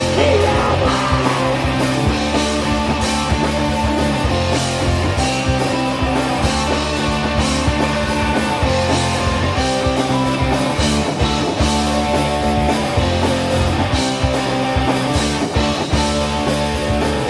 Hilfe bei Punkrock Setup ("Matsch", kaum Durchsetzung im Band-Mix)
Hab gestern mal ganz primitiv mit dem Handy eine Aufnahme gemacht… bei dem Soloteil spiele ich die Rhythmus Gitarre, hätte noch jemand Verbesserungsvorschläge bzgl der Amp Einstellungen?